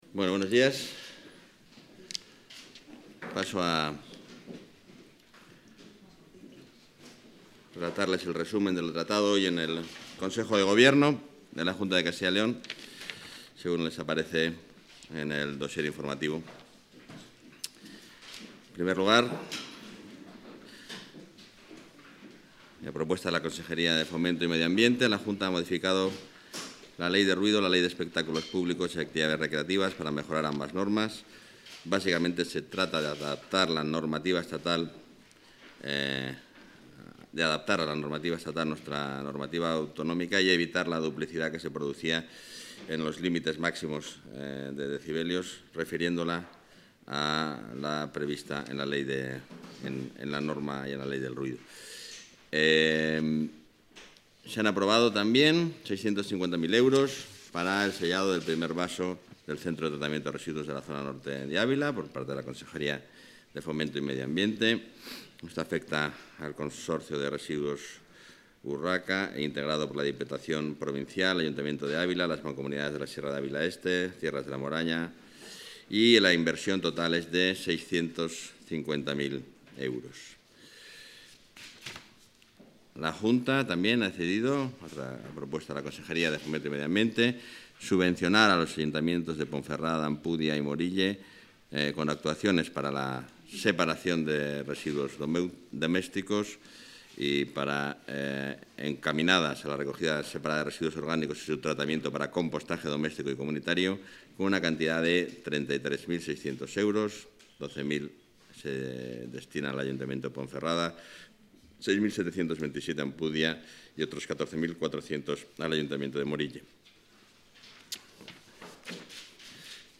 Rueda de prensa tras Consejo de Gobierno.